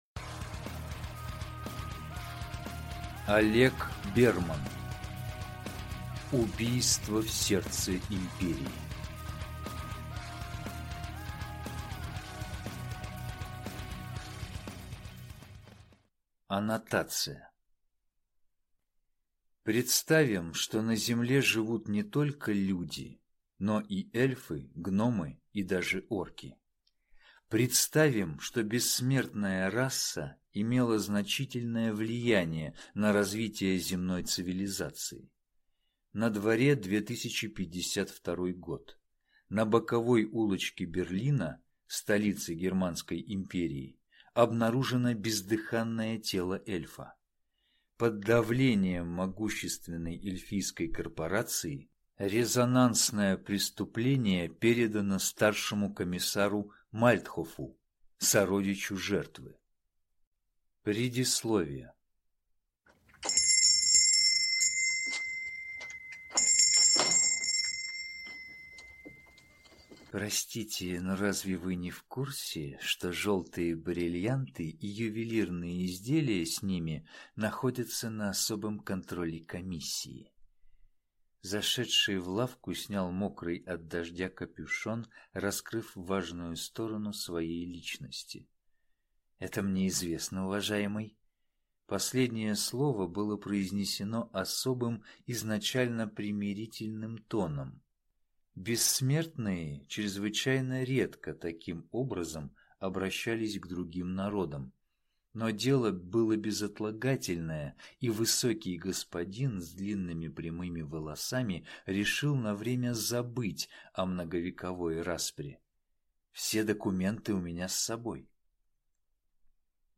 Аудиокнига Убийство в сердце империи | Библиотека аудиокниг